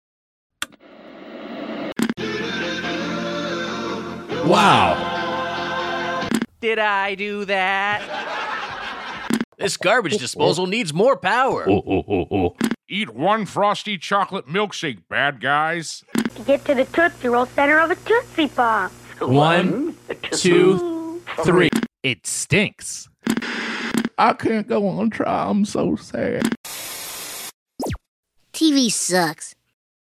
Doughboys is a podcast about chain restaurants. In each episode, they play a listener-submitted audio clip that is usually a mashup or remix of audio from previous episodes.
Imagine you had a fever dream where ’90s TV merged with Doughboys — that’s the premise of this drop.